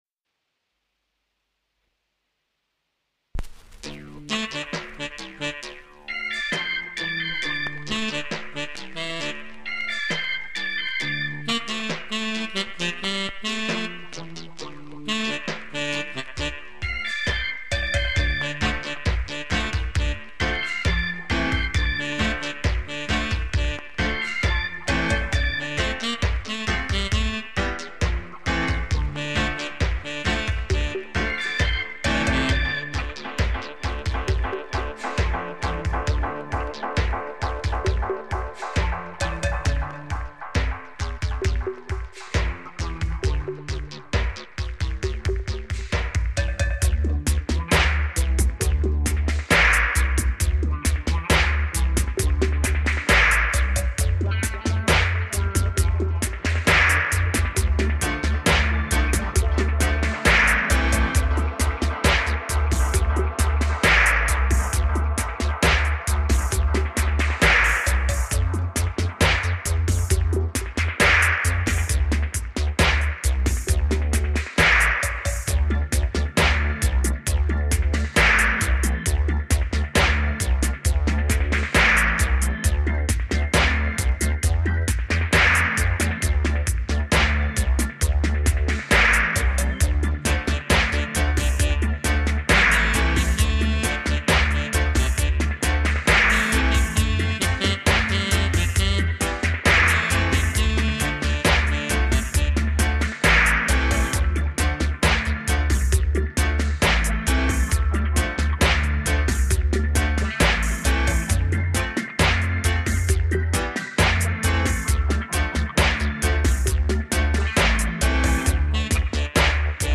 original formula dub plate. http